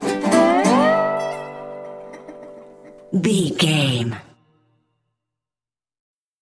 Ionian/Major
electric guitar
acoustic guitar
drums